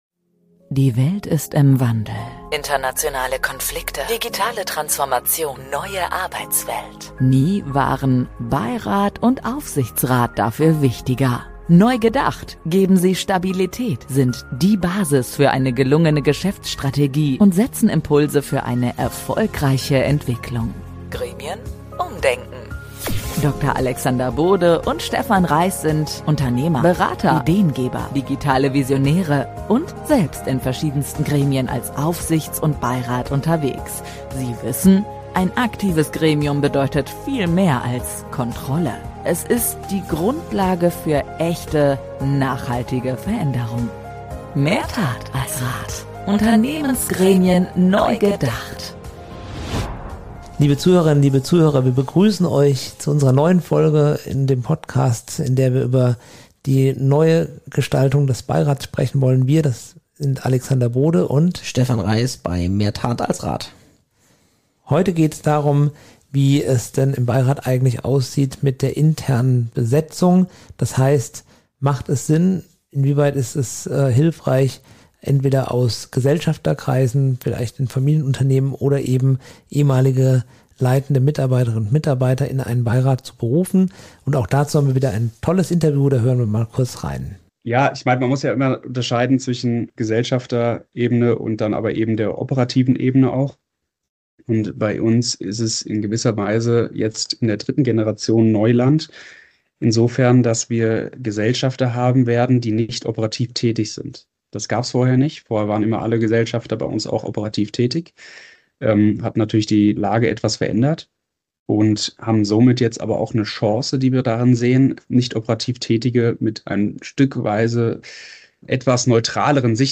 Dabei wird klar: Interne Nähe kann wertvoll sein, weil sie Geschichte, Kultur und Dynamiken des Unternehmens kennt – gleichzeitig wächst aber das Risiko von Betriebsblindheit, Rollenkonflikten und alten Loyalitäten. Besonders kritisch beleuchten die Hosts den Wechsel eines früheren Geschäftsführers in den Beirat und zeigen Alternativen auf, die das Unternehmen unterstützen, ohne neue Unklarheiten in der Organisation zu erzeugen.